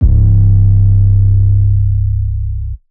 Major 808 3.wav